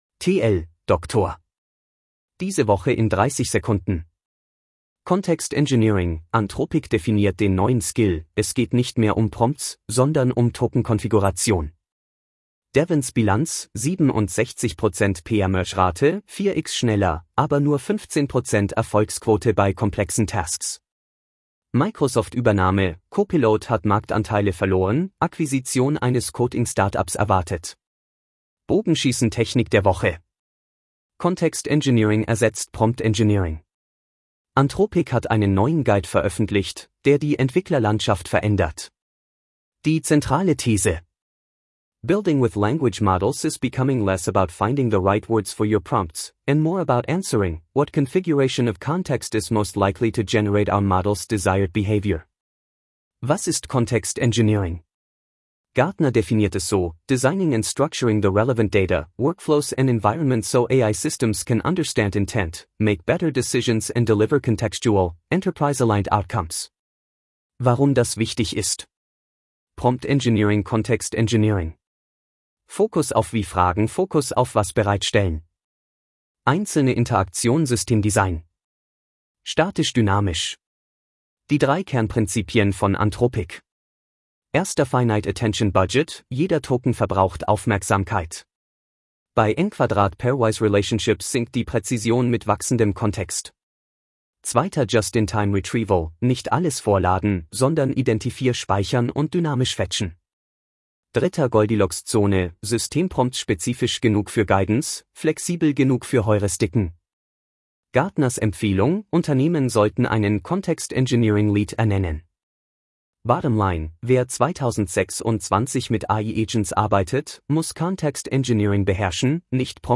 Vorgelesen mit edge-tts (Microsoft Azure Neural Voice: de-DE-ConradNeural)